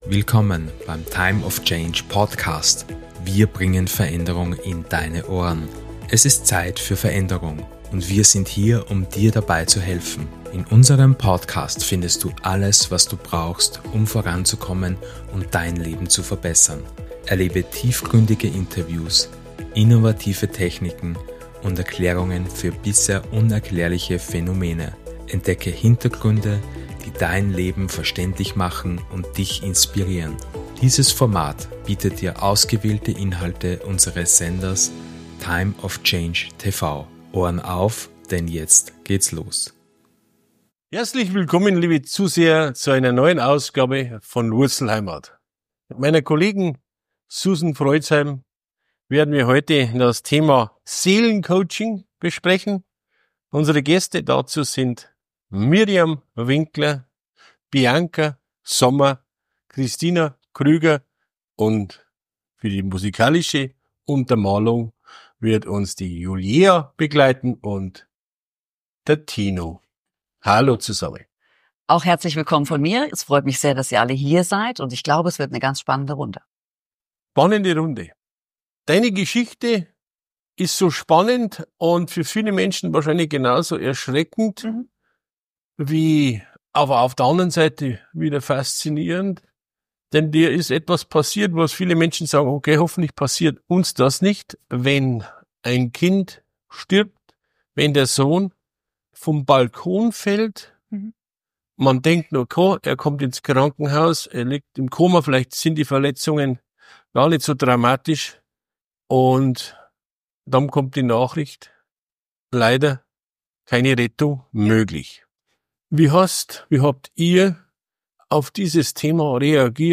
Du erlebst eine bewegende und inspirierende Diskussion mit besonderen Gästen, die ihre einzigartigen Perspektiven und Erfahrungen teilen.
WurzlHeimat - Der Talk ins Unbekannte